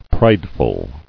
[pride·ful]